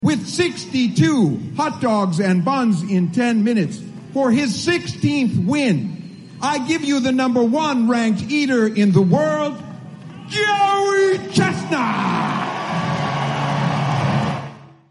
Competitive eating champion Joey Chestnut continued his winning tradition on Tuesday, celebrated the Fourth of July by winning Nathan’s Famous Fourth of July International Hot Dog Eating Contest in Coney Island for the 16th time. Here’s the winning call as heard on ESPN…